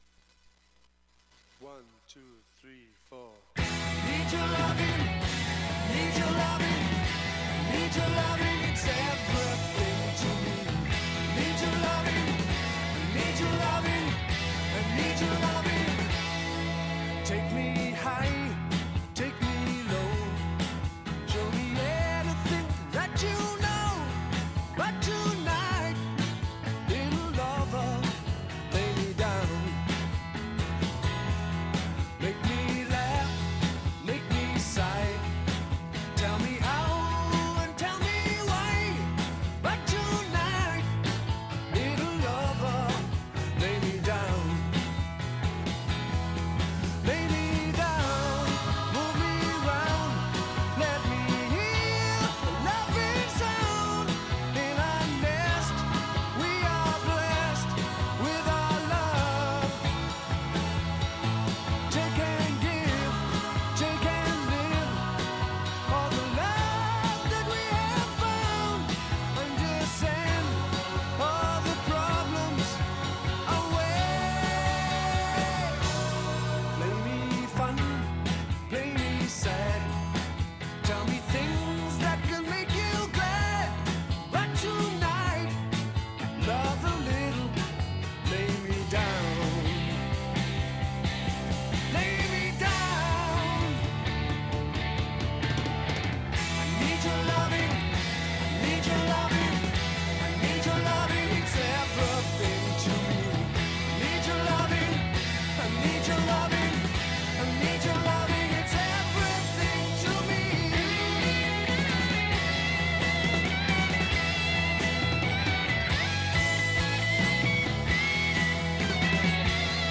Classic Rock Sound: Head First
But this hour of music was my definition of sorts, and I hope you all had a good time listening and a lovely Valentines weekend!